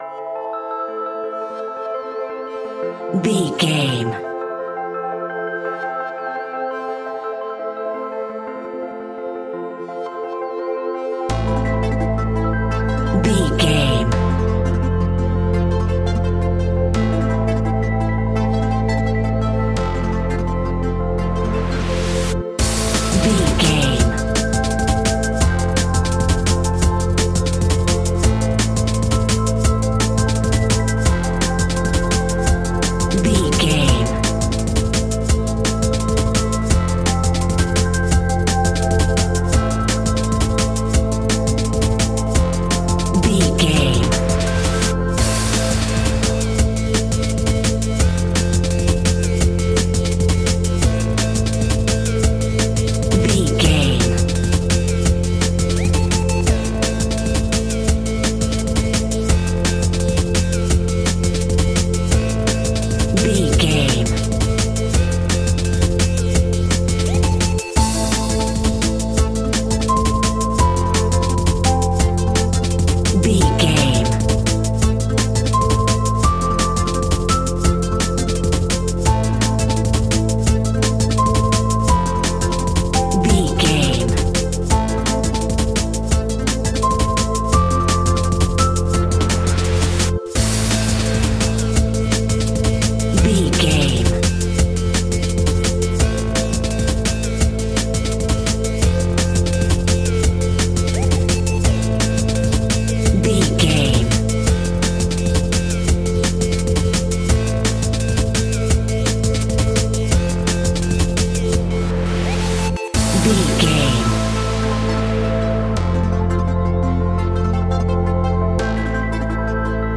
Aeolian/Minor
Fast
aggressive
groovy
frantic
drum machine
synthesiser
darkstep
sub bass
Neurofunk
synth leads
synth bass